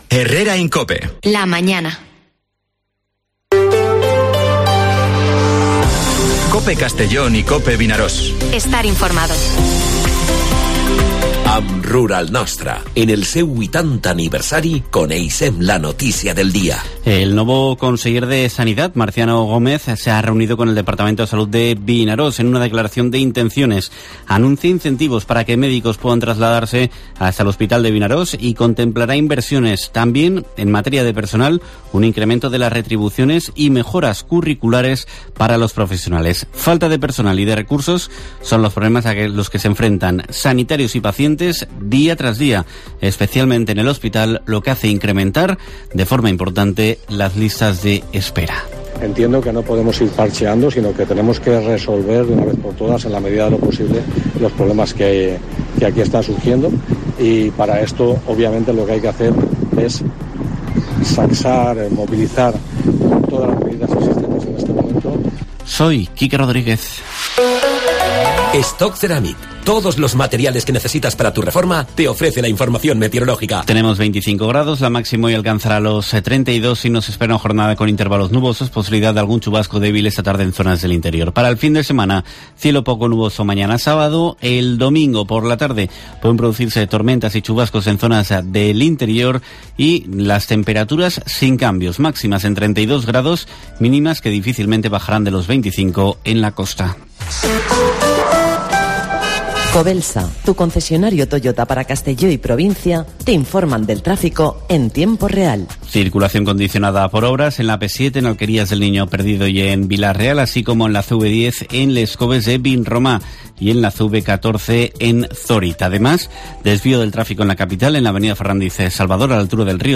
Informativo Herrera en COPE en la provincia de Castellón (28/07/2023)